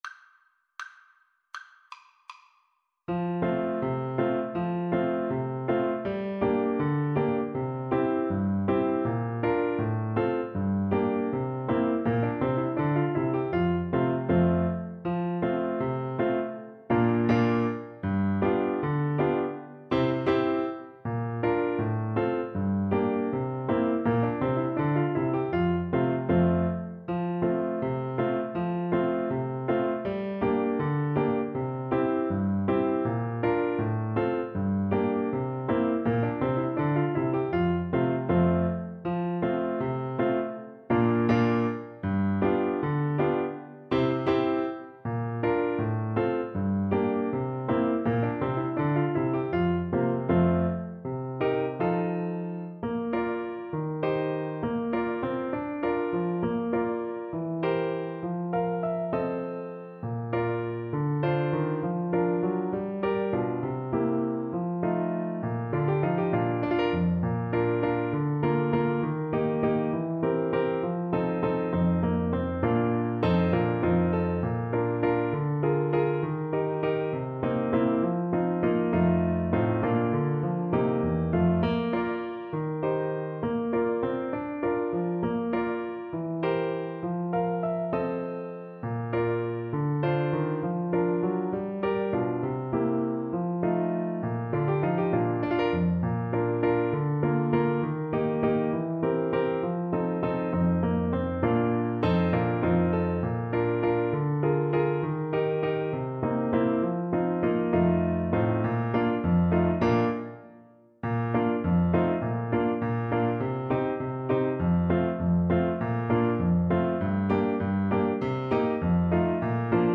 Cello version
4/4 (View more 4/4 Music)
D3-D5